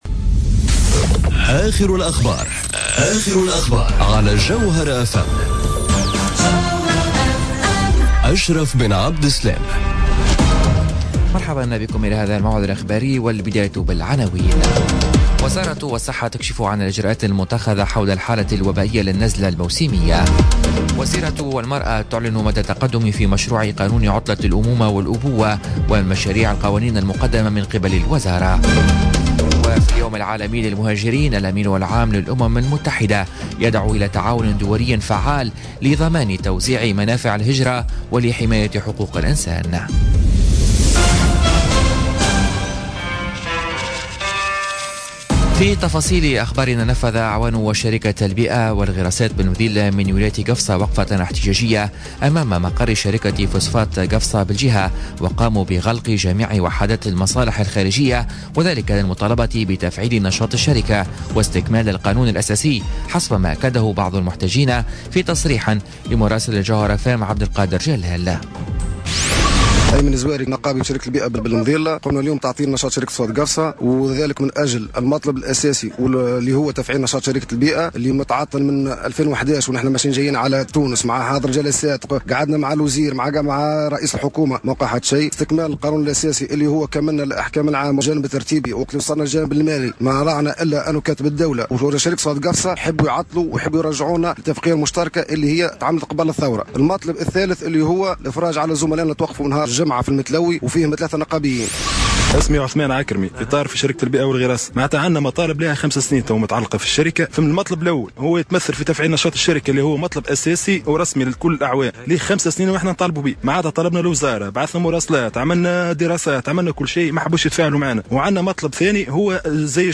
نشرة أخبار منتصف النهار ليوم الإثنين 18 ديسمبر 2017